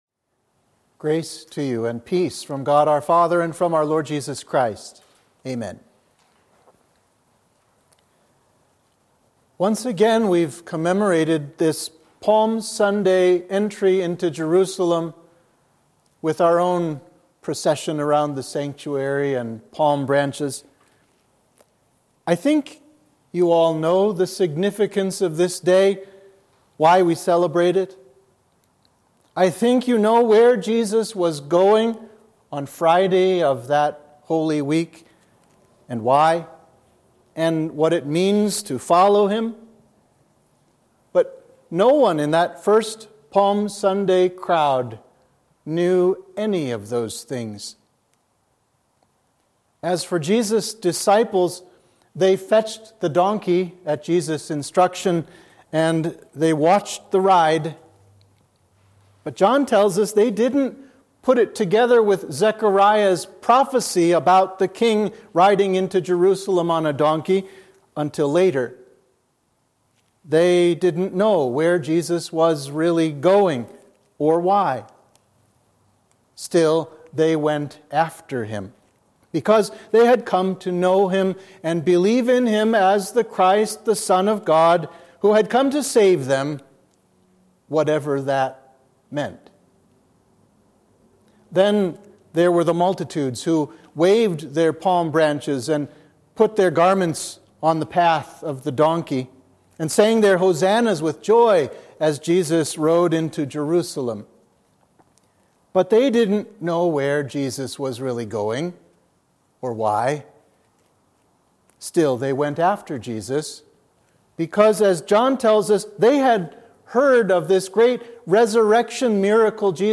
Sermon for Palm Sunday